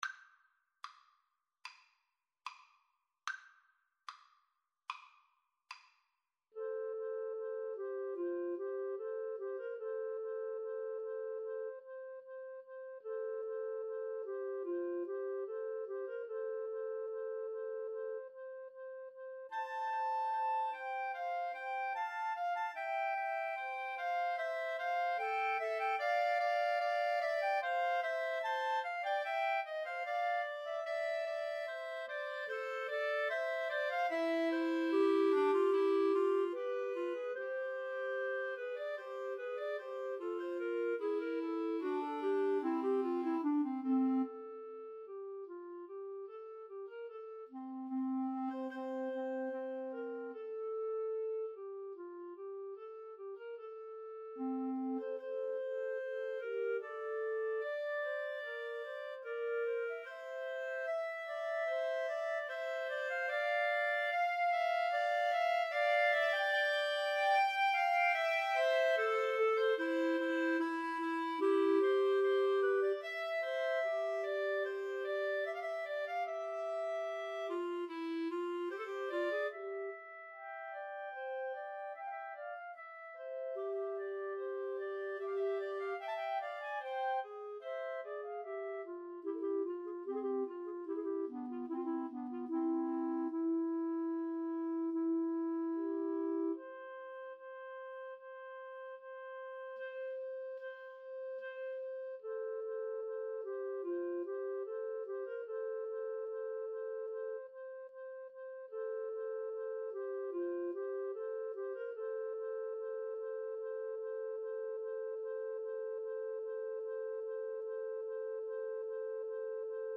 ~ = 74 Moderato
Clarinet Trio  (View more Intermediate Clarinet Trio Music)
Classical (View more Classical Clarinet Trio Music)